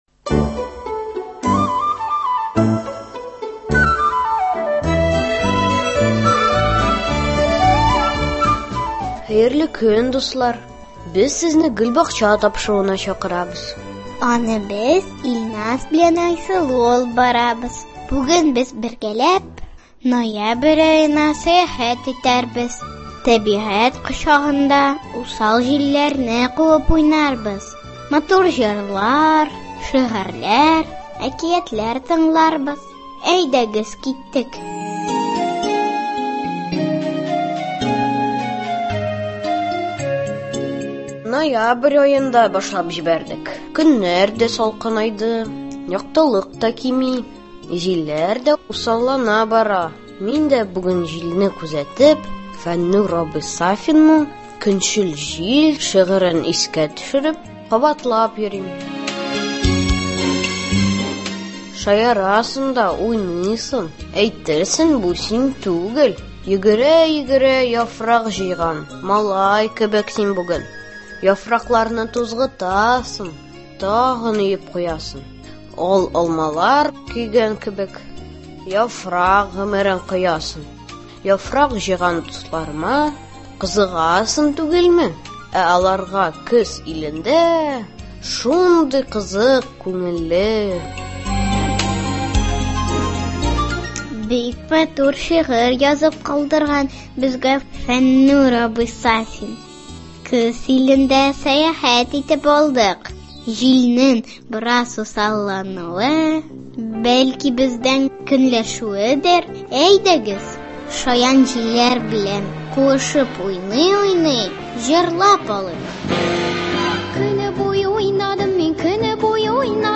Безгә нәни дусларыбыз да кушыла.